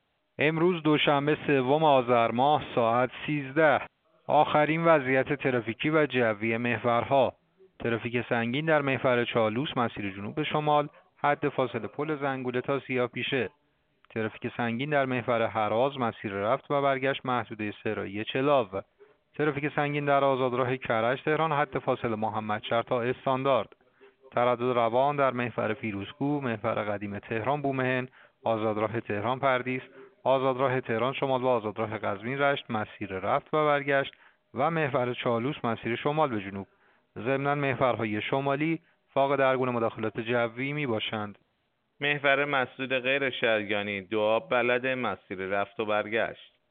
گزارش رادیو اینترنتی از آخرین وضعیت ترافیکی جاده‌ها ساعت ۱۳ سوم آذر؛